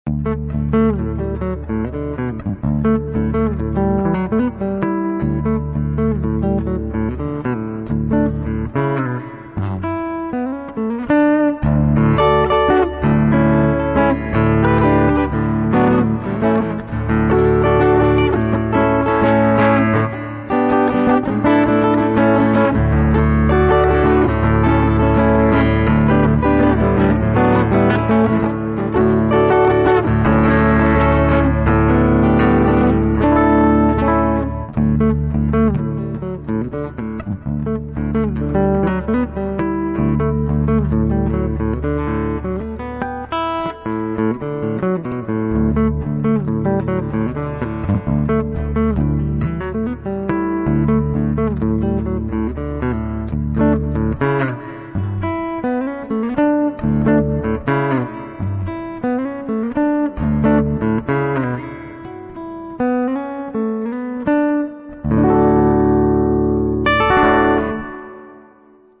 Acoustic piece
Played with VG-88 processor and an electric guitar.